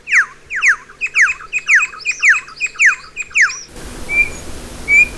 "Turpial Venezolano"
Icterus icterus
turpial.wav